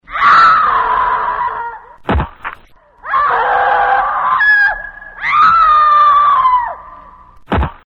cleaver.mp3